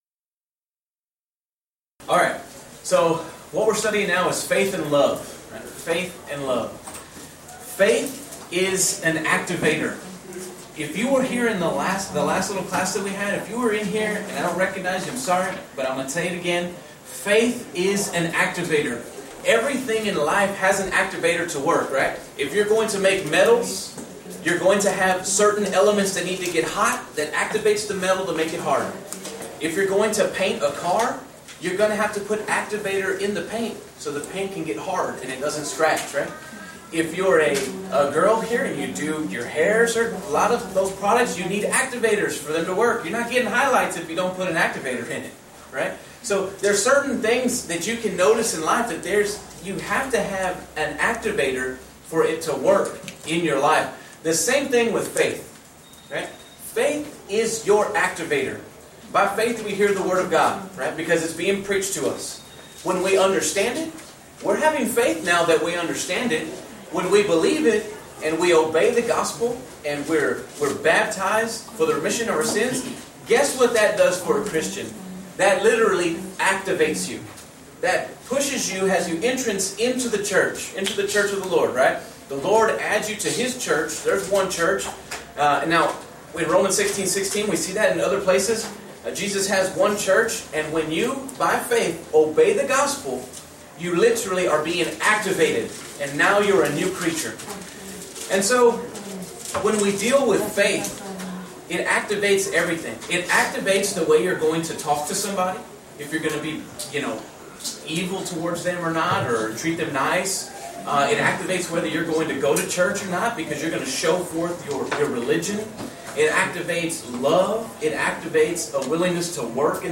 Alternate File Link File Details: Series: Discipleship University Event: Discipleship University 2013 Theme/Title: Dead or Alive: Lessons about faith from a man who served Jesus.
Youth Sessions